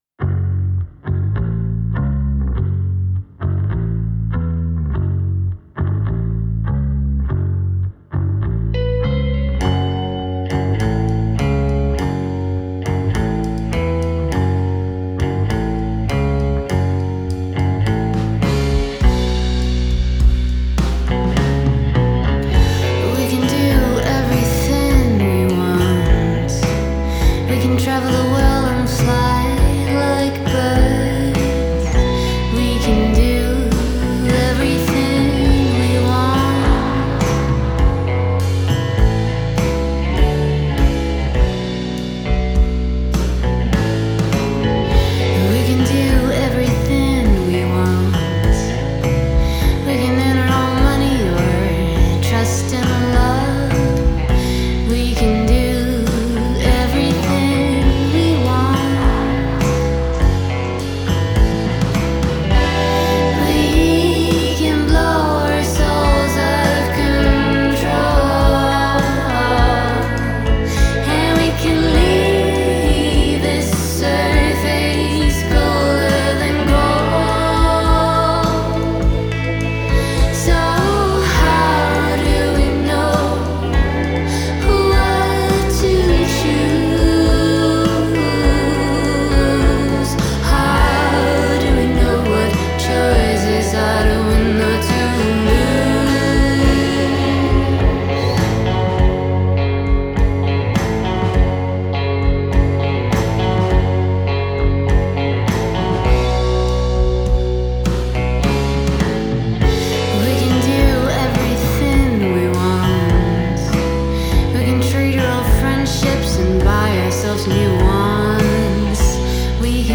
Genre: Indie, Rock, Pop